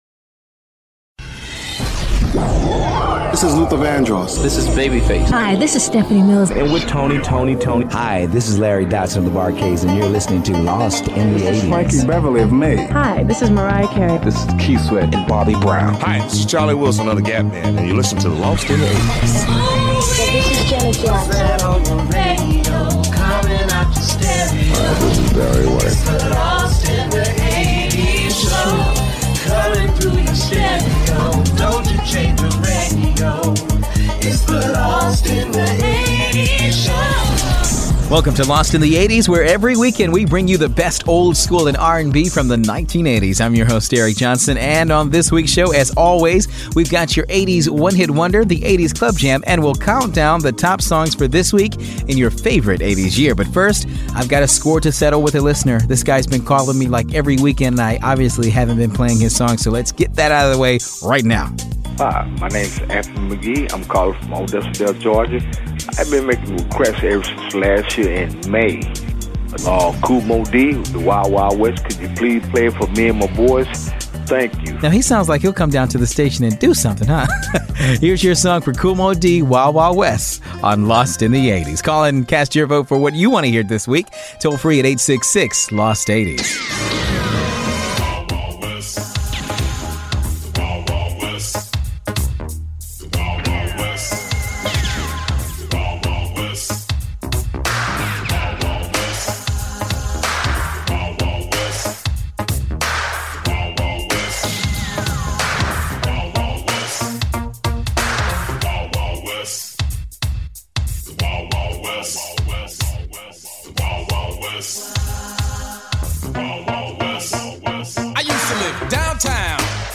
weekly radio show
plays upbeat, old skool and R&B hits from the 1980s